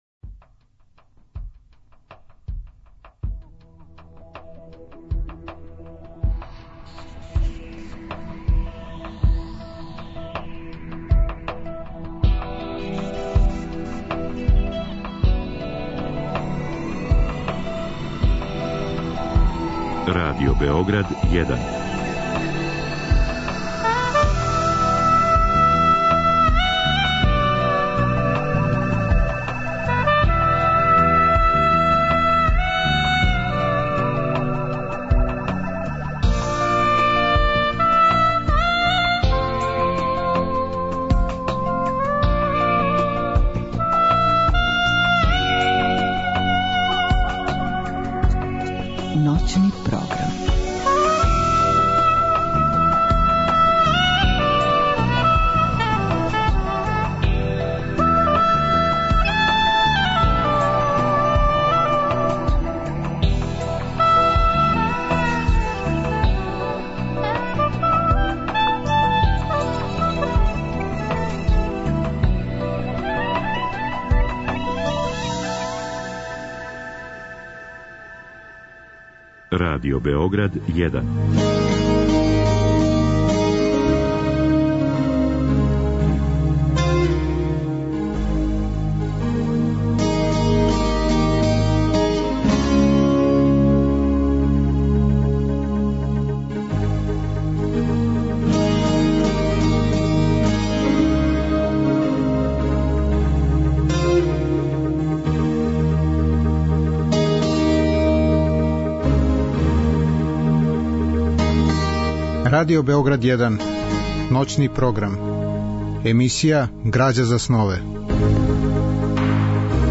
Разговор и добра музика требало би да кроз ову емисију и сами постану грађа за снове.
Биће речи о култури говора, о неговању језика, о речима, о Вуковом Рјечнику и другом речницима српског језика. У другом делу емисије, од два до четири часa ујутро, слушаћемо делове радио-драма посвећених Вуку Стефановићу Караџићу и питањима српског језика.